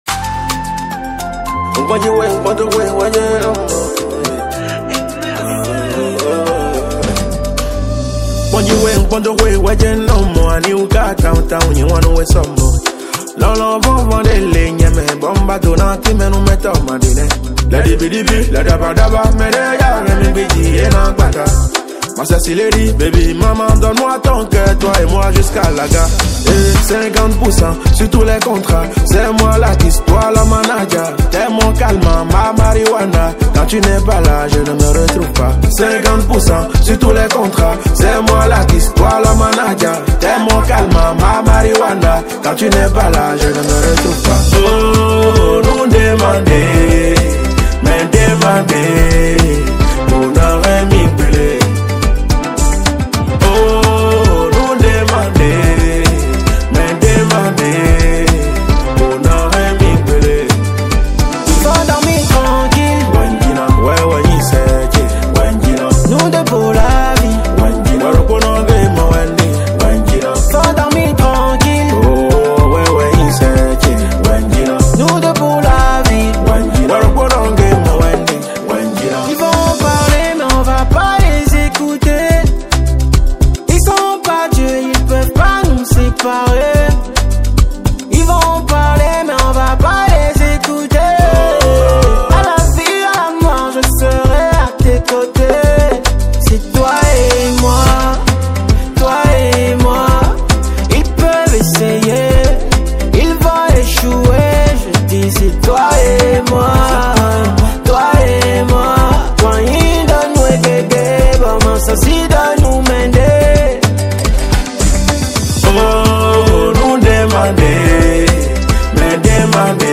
| Afro pop